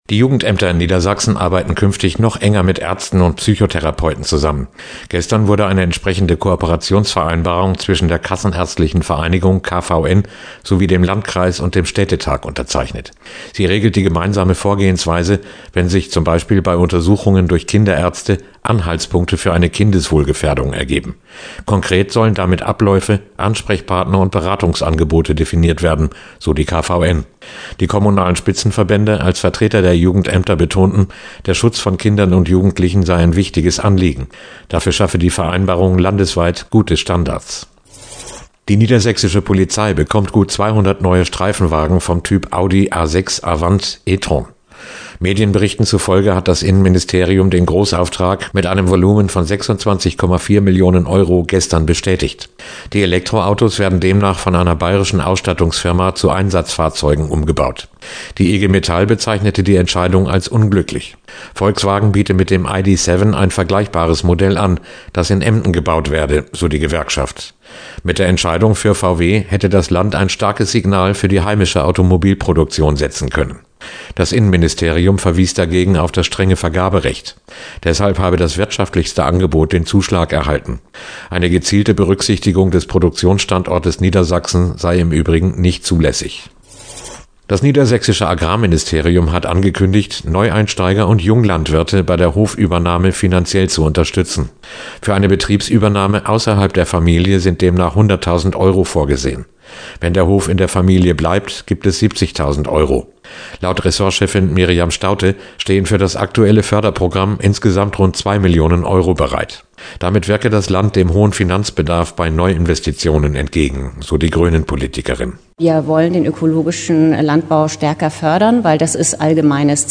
Am 7. Oktober 2023 wurden beim Angriff der Terror-Organisation Hamas auf Israel fast 1200 Menschen getötet. Am heutigen zweiten Jahrestag finden in vielerorts in Niedersachsen Gedenkveranstaltungen statt – so auch in Braunschweig: Die Deutsch-Israelische Gesellschaft lädt am Abend zu einer Kundgebung auf dem Platz der Deutschen Einheit ein. Weitere Meldungen aus unserem Bundesland